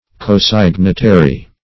Cosignitary \Co*sig"ni*ta*ry\ (k?-s?g"n?-t?-r?), a. [Pref. co- +